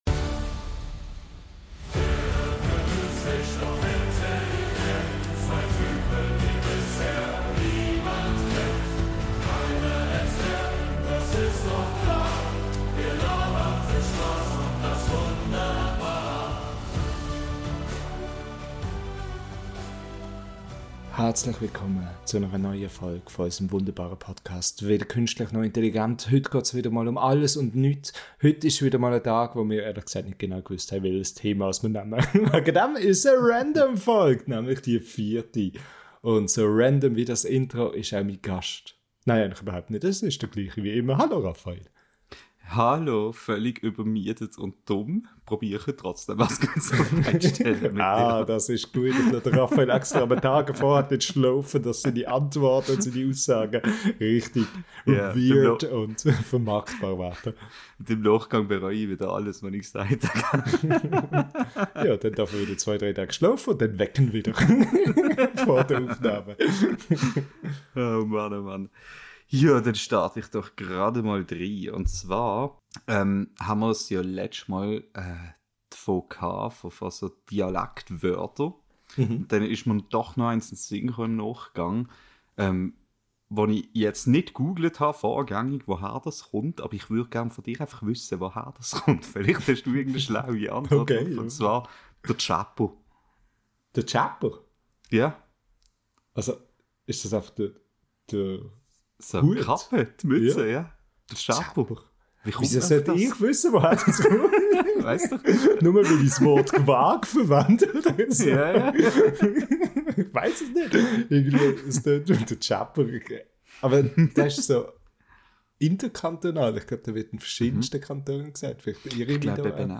In dieser neuen Folge unseres schweizerdeutschen Podcasts holen wir wieder einmal unseren Kesselbuntes hervor.